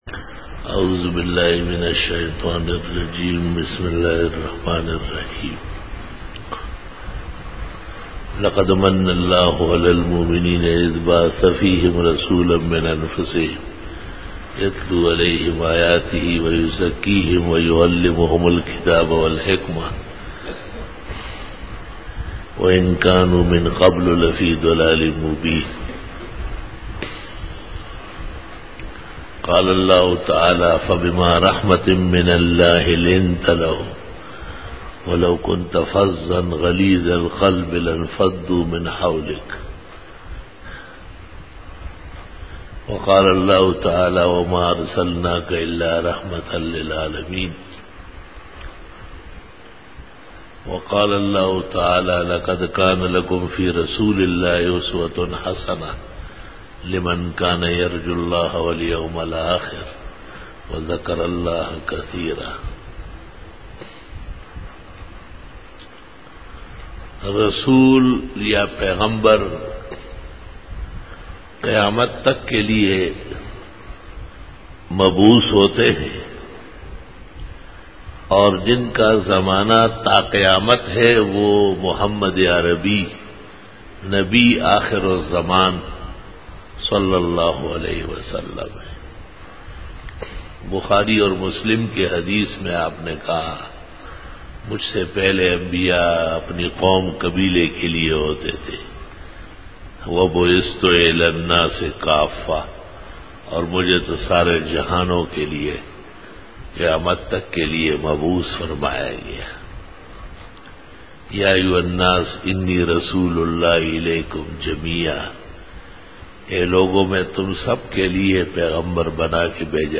02 Bayan e juma tul mubarak 11-january-2013
Khitab-e-Jummah 2013